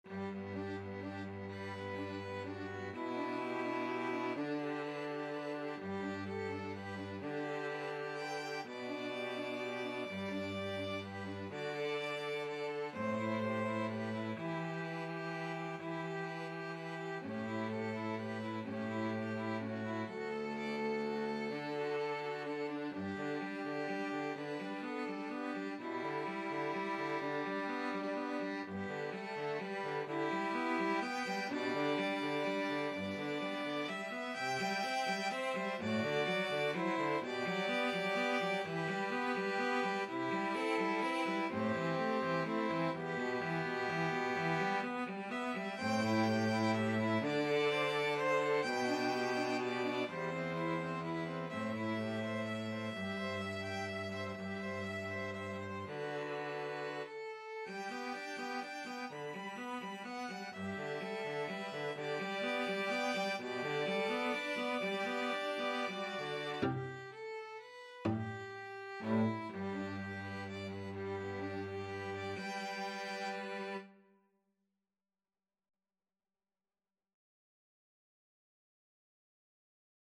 Violin 1Violin 2Cello
G major (Sounding Pitch) (View more G major Music for 2-Violins-Cello )
6/8 (View more 6/8 Music)
Andante ingueno .=42
Classical (View more Classical 2-Violins-Cello Music)